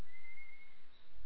Step Up Rat call
Step-Up-Call-USV-Audio-File.wav